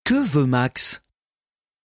Dog - Living Room - Dog Language [Quiz] | Purina FR
dog_languages_quiz_question3_fr_1.mp3